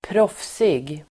Ladda ner uttalet
proffsig adjektiv (vardagligt), professional [informal]Uttal: [²pr'åf:sig] Böjningar: proffsigt, proffsigaDefinition: professionell
proffsig.mp3